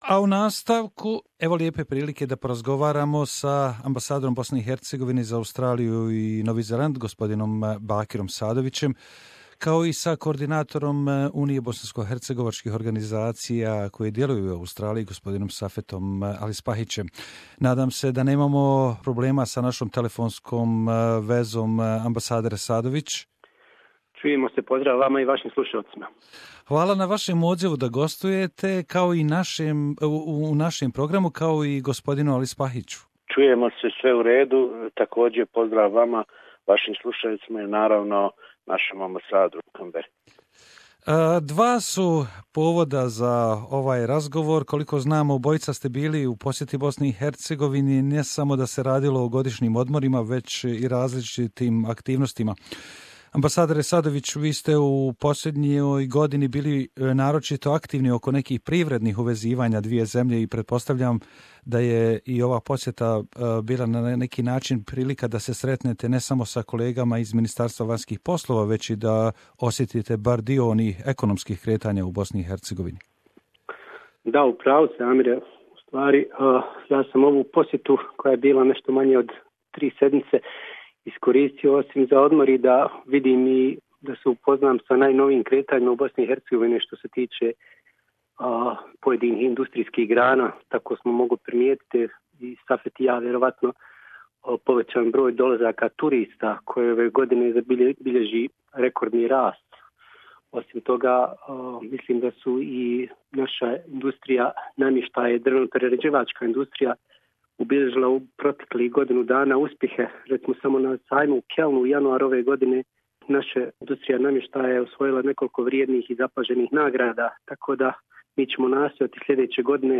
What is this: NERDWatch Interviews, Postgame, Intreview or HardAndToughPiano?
Intreview